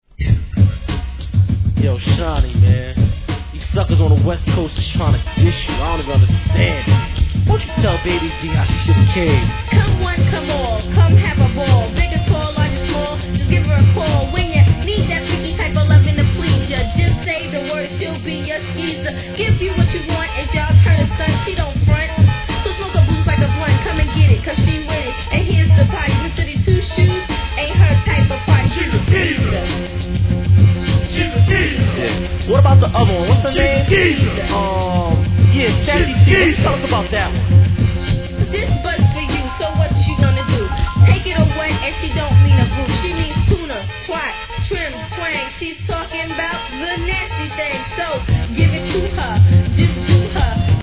HIP HOP/REGGARE/SOUL/FUNK/HOUSE/